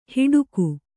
♪ hiḍuku